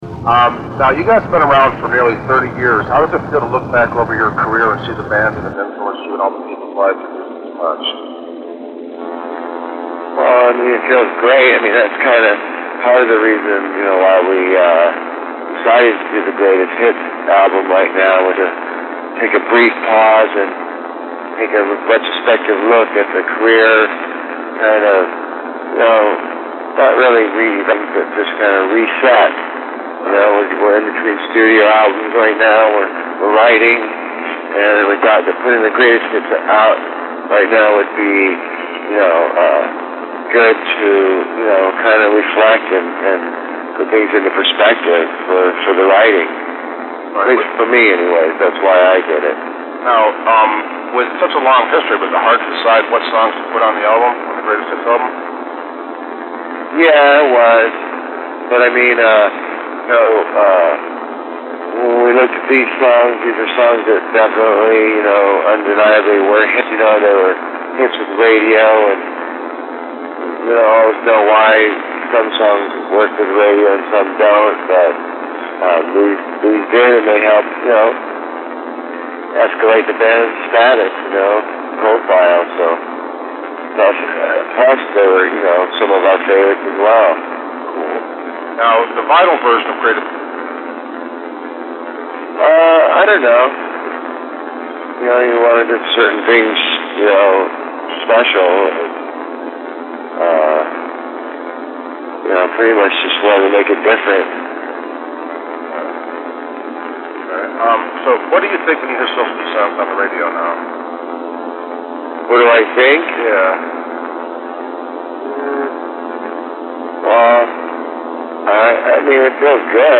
The new recorder and mic I got picked up a lot of background noise. I got out as much as I could but it still sounds very rough. MIKE NESS INTERVIEW